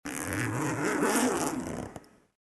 Звуки молнии, одежды
На этой странице собраны звуки молний на одежде — от резких до плавных, с разными типами тканей.
Звук застегиваемых осенних сапог